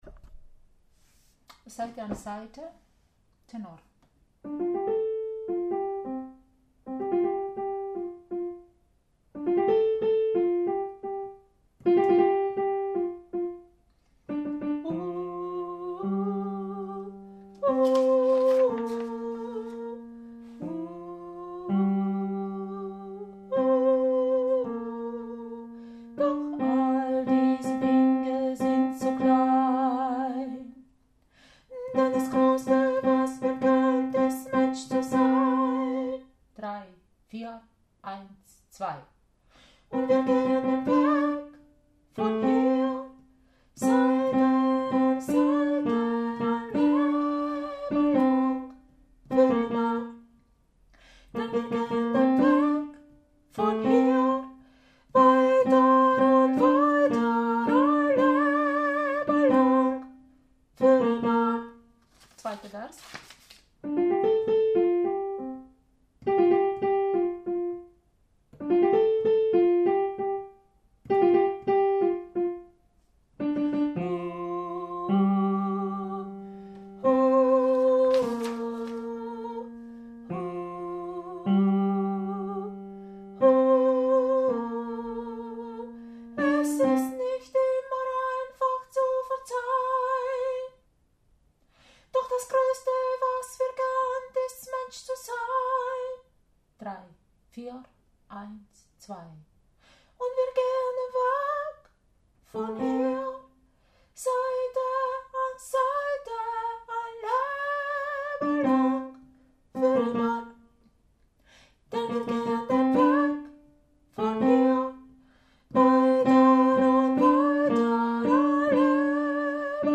Seite an Seite – Tenor
Seite-an-Seite-Tenor.mp3